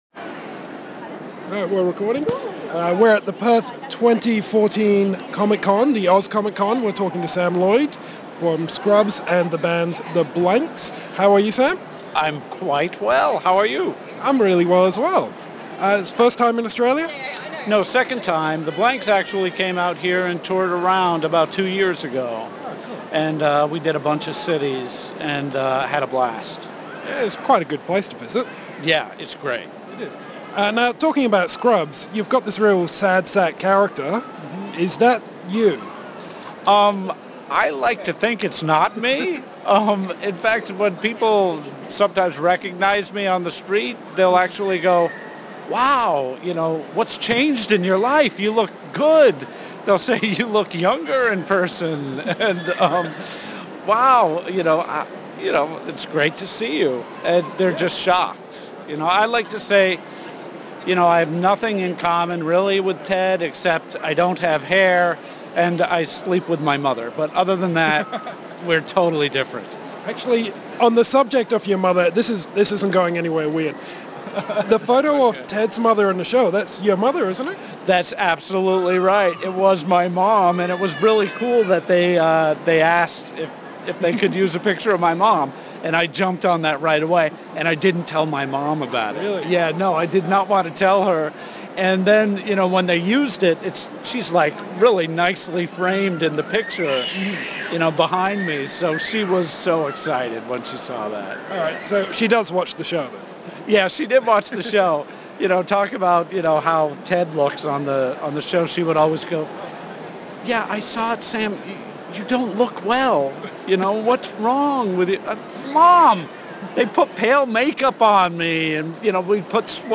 NB: Don’t be alarmed by the sound of a shrieking child.